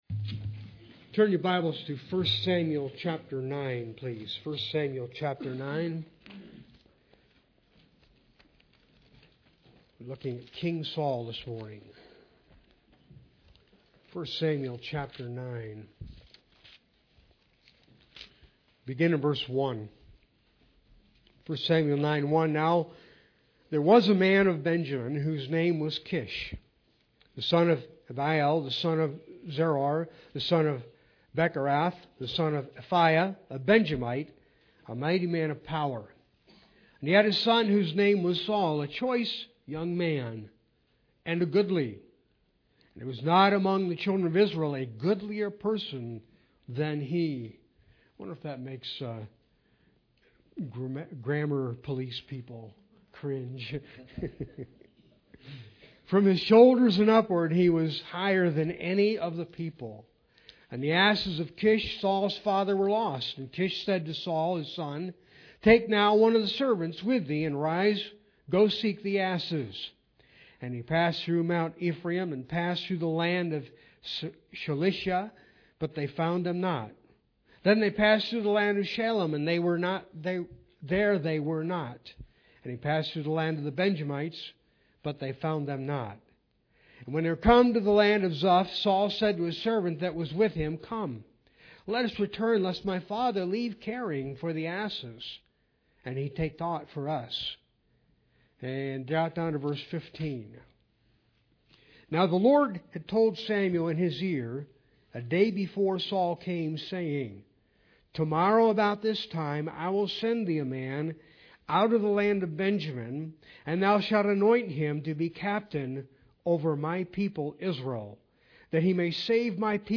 I Samuel 9:1-5 Service Type: Sunday Worship Bible Text